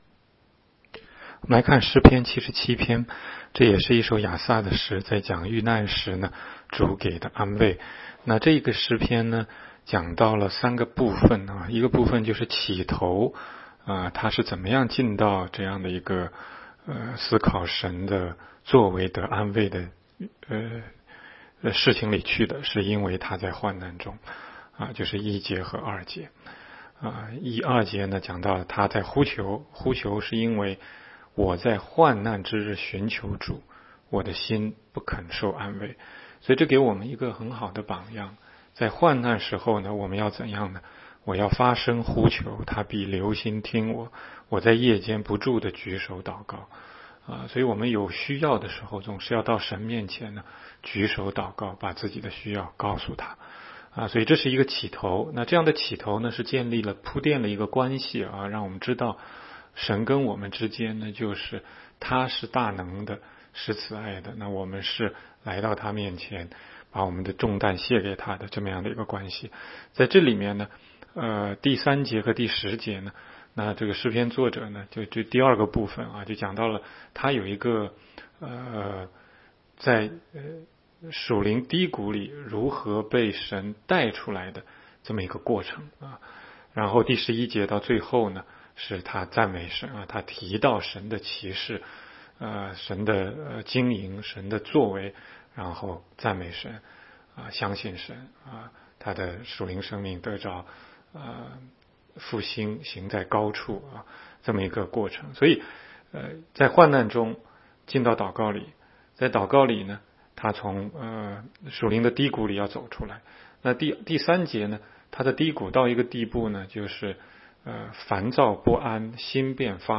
16街讲道录音 - 每日读经-《诗篇》77章